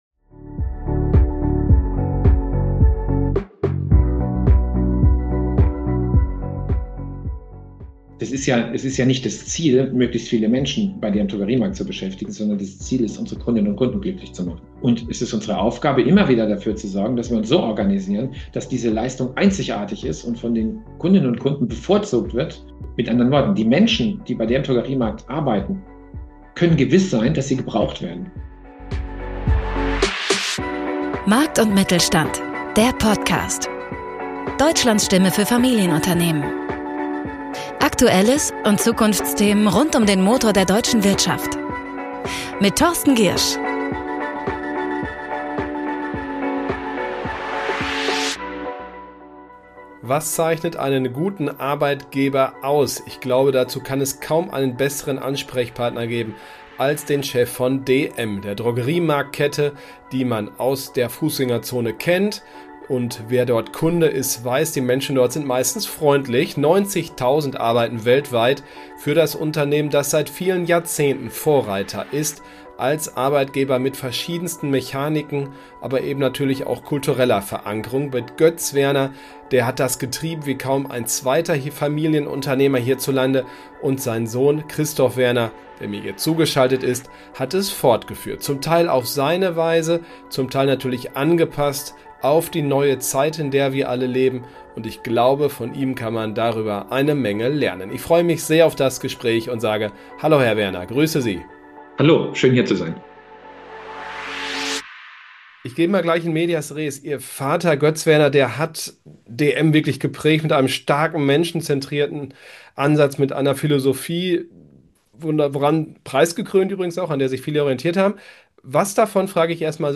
DM-CEO Christoph Werner über dialogische Führung, Selbstwirksamkeit und warum Regeln Organisationen oft eher bremsen als stärken. Ein Gespräch über Arbeit, Sinn, Hierarchie – und was gute Arbeitgeber wirklich ausmacht.